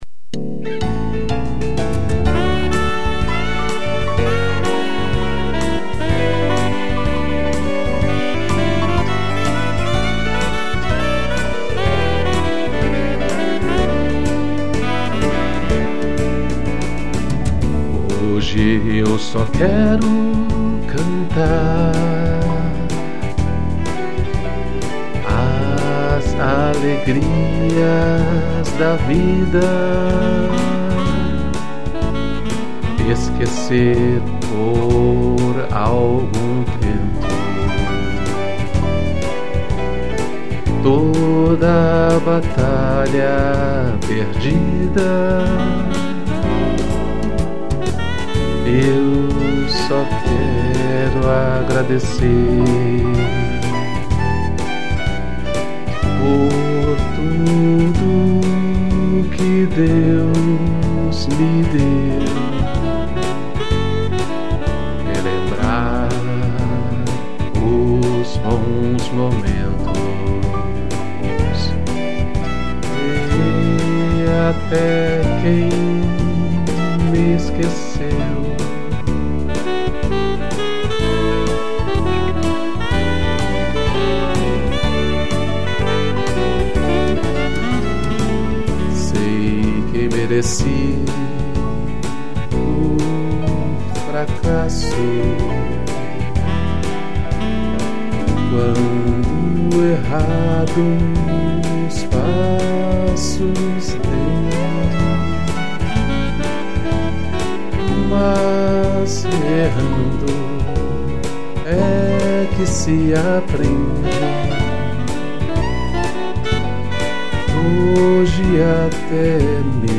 piano, sax e violino